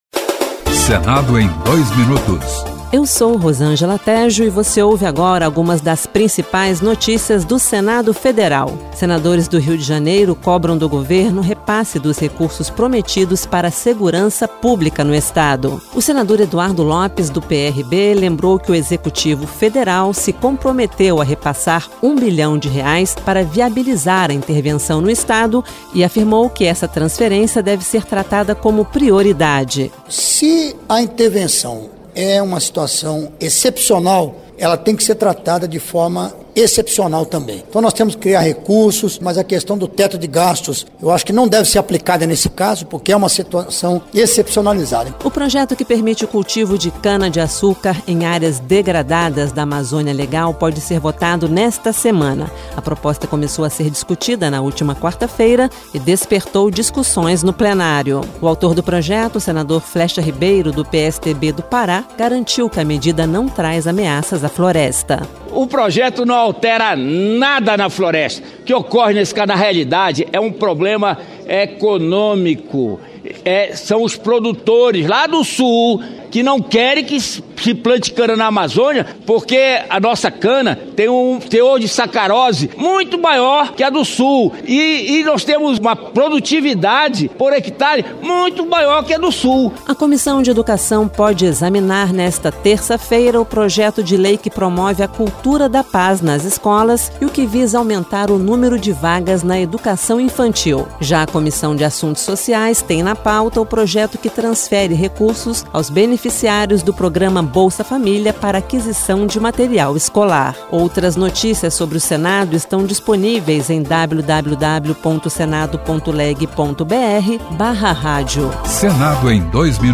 O Senado em Dois Minutos desta segunda-feira (26) destaca a cobrança dos senadores ao governo federal para repassar os recursos prometidos à segurança pública do Rio de Janeiro. O boletim também ressalta alguns projetos que estão na pauta de votações do Plenário e das comissões: permissão do cultivo de cana-de-açúcar em áreas degradadas da Amazônia Legal, promoção da cultura de paz nas escolas e ampliação do número de vagas em creches.